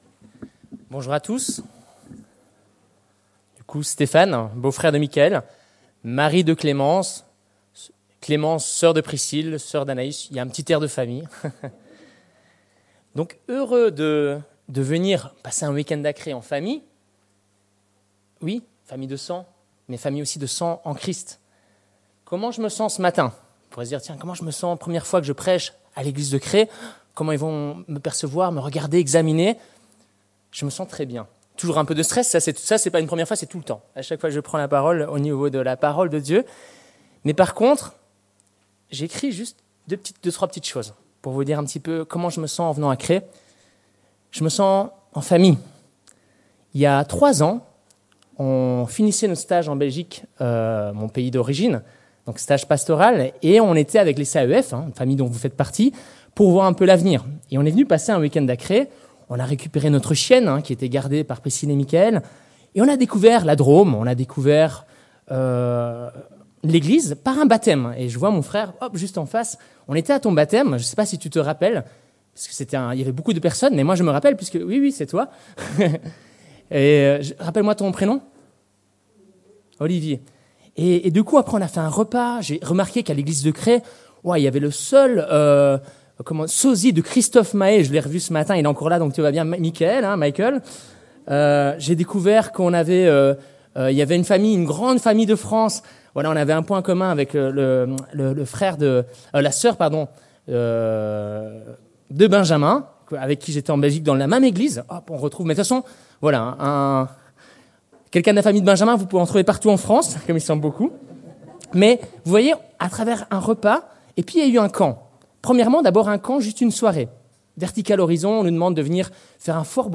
Deutéronome Prédication textuelle